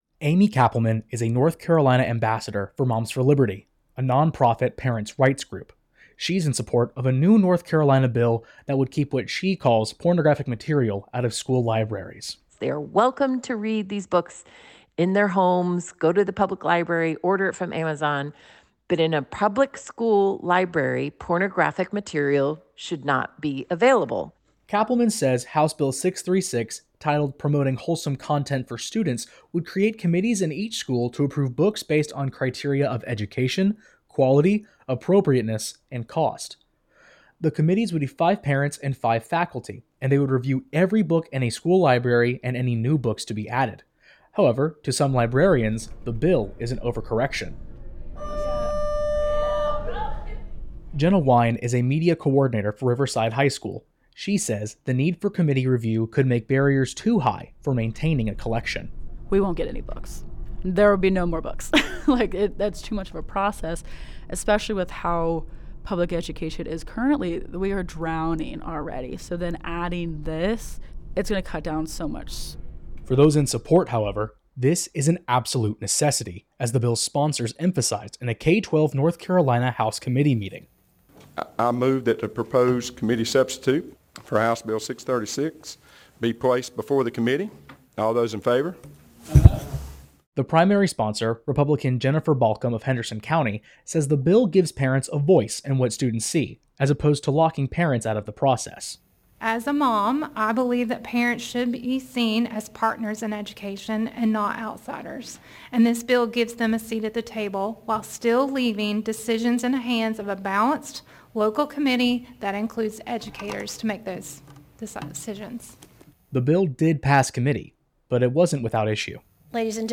Audio report on the potential impact of a new bill that could make it easier for parents to block books from school libraries.